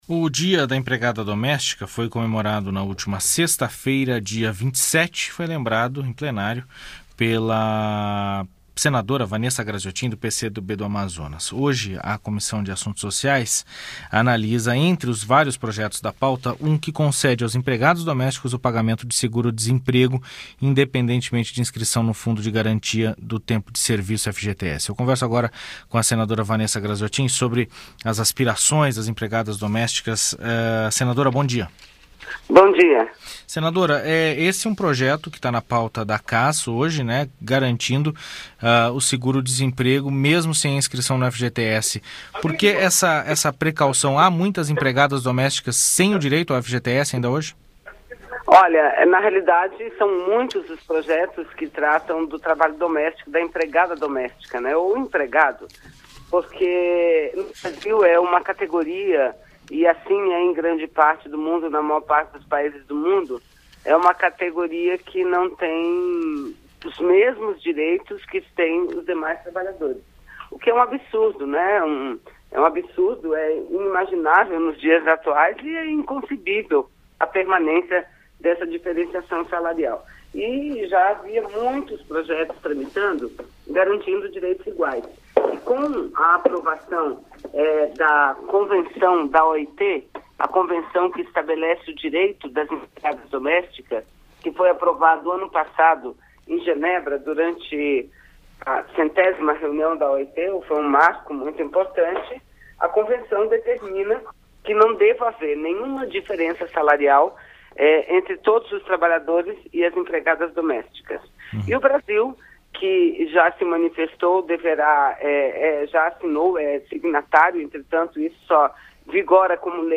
Entrevista com a senadora Vanessa Grazziotin (PCdoB-AM).